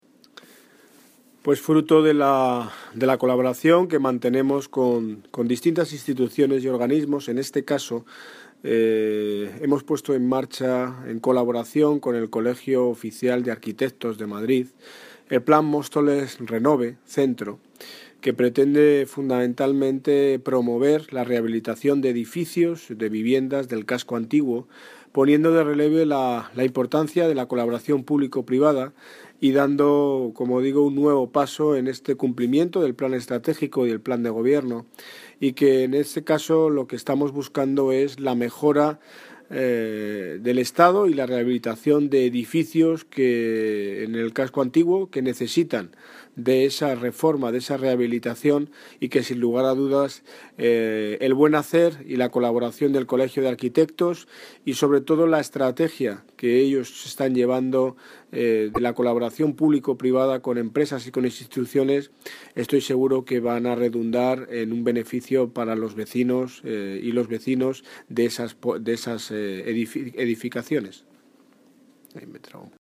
Audio - Daniel Ortiz (Alcalde de Móstoles) Sobre Plan Renove Centro